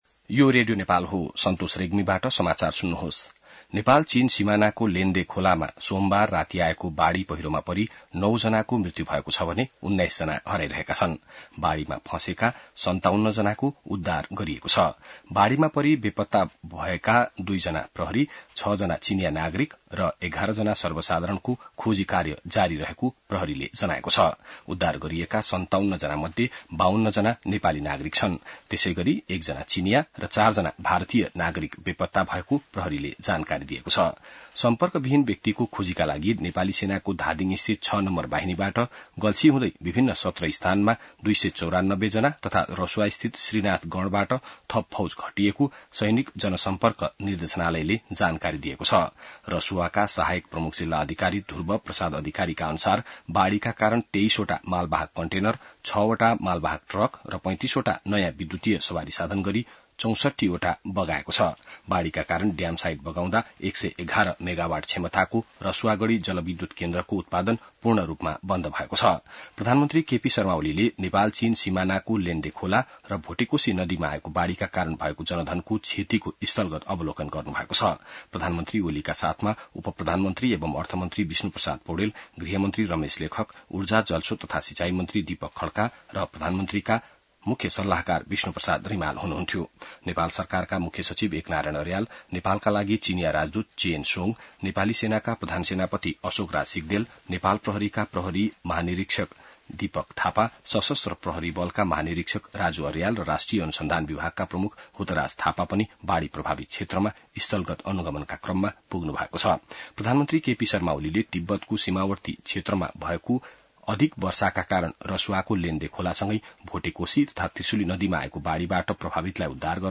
An online outlet of Nepal's national radio broadcaster
बिहान ६ बजेको नेपाली समाचार : २५ असार , २०८२